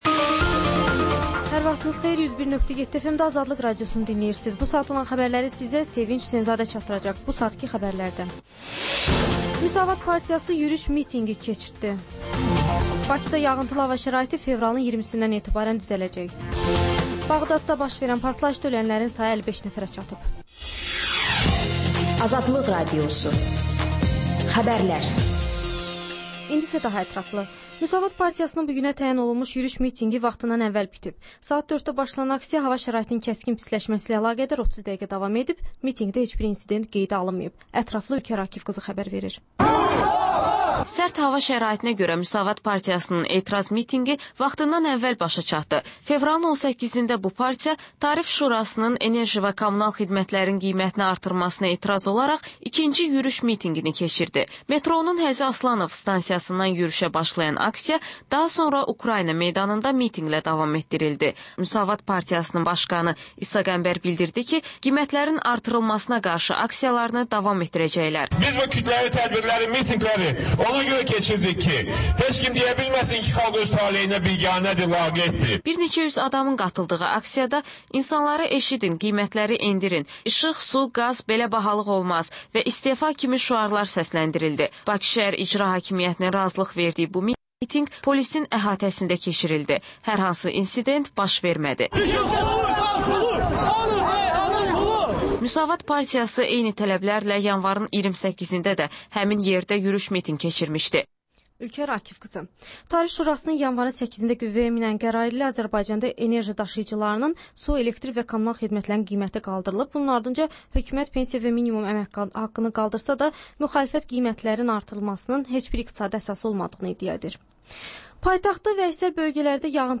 Xəbərlər, reportajlar, müsahibələr.